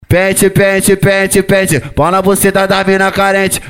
Download “Acapella